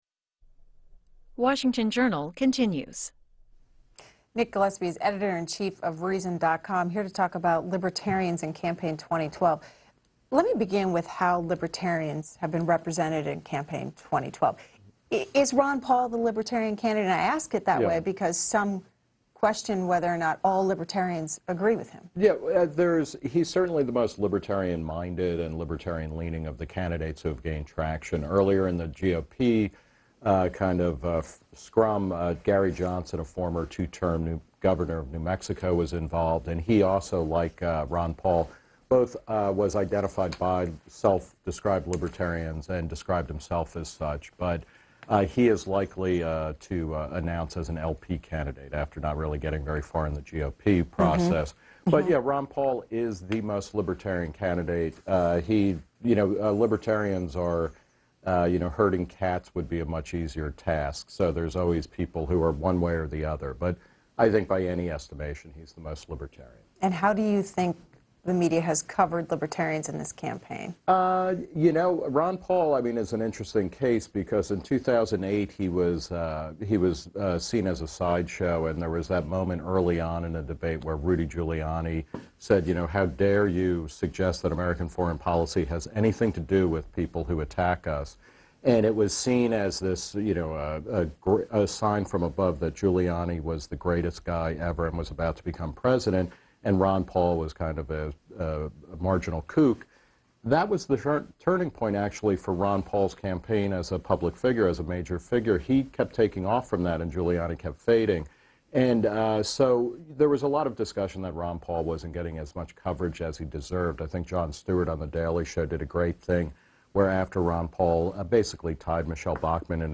Reason's Nick Gillespie appeared on C-SPAN's Washington Journal to discuss the 2012 GOP campaign, libertarianism in mainstream politics, and Ron Paul's rise. Gillespie also responded to Q&A from callers.